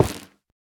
Minecraft Version Minecraft Version snapshot Latest Release | Latest Snapshot snapshot / assets / minecraft / sounds / block / stem / break1.ogg Compare With Compare With Latest Release | Latest Snapshot
break1.ogg